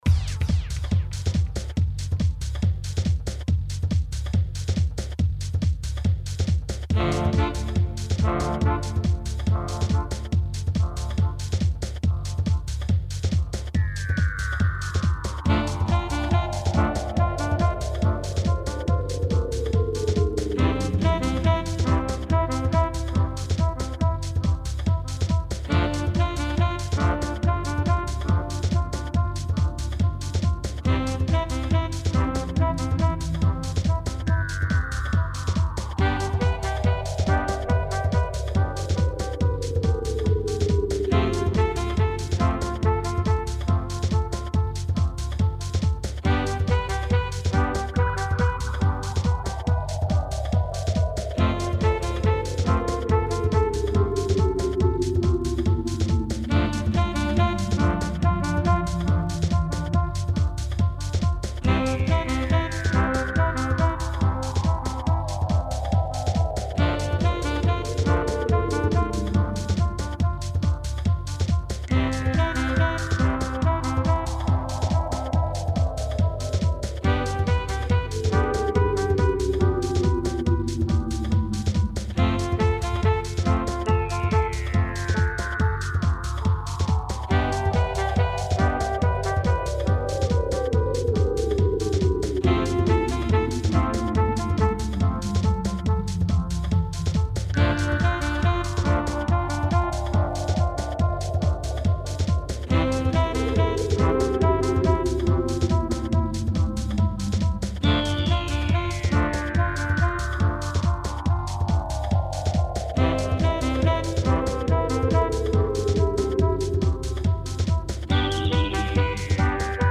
Tempo: 70 bpm / Datum: 16.09.2018